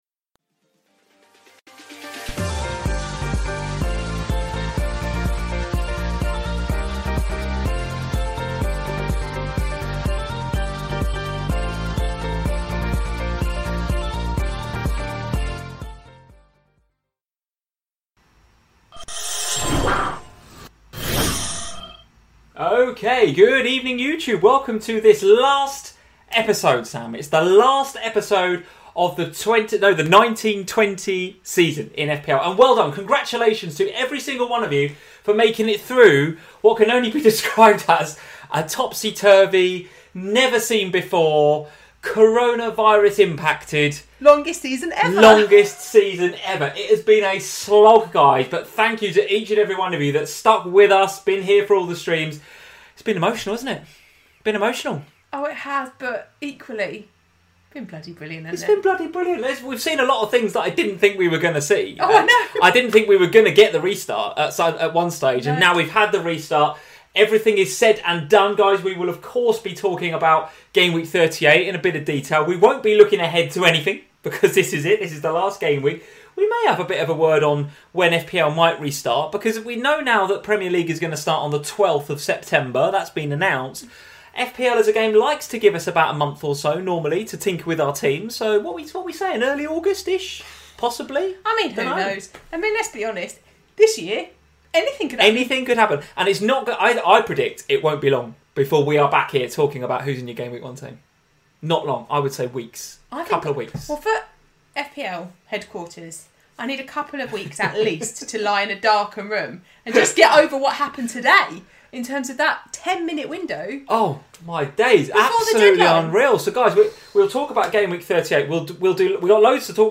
Welcome to FPL Family, a chat show dedicated to all things Fantasy Premier League.